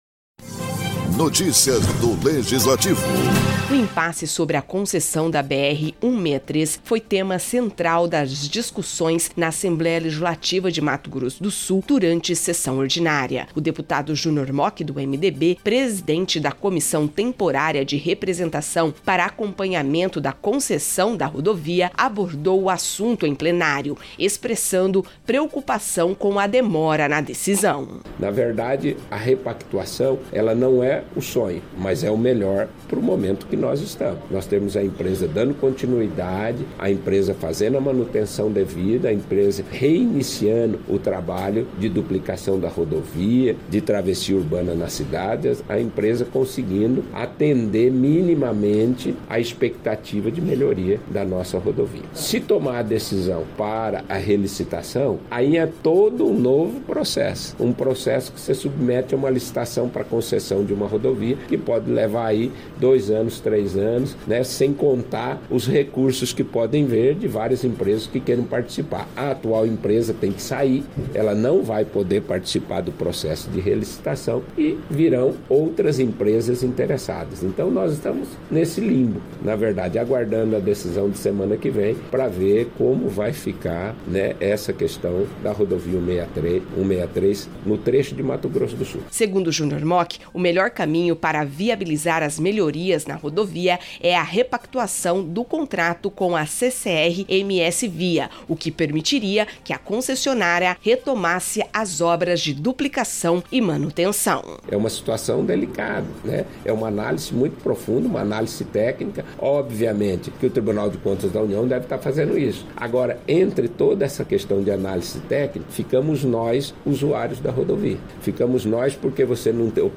O impasse sobre a concessão da BR-163 foi tema central das discussões na Assembleia Legislativa de Mato Grosso do Sul (ALEMS), durante sessão ordinária. O deputado Junior Mochi (MDB), presidente da Comissão Temporária de Representação para Acompanhamento da Concessão da BR-163, abordou o assunto em plenário.